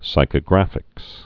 (sīkə-grăfĭks)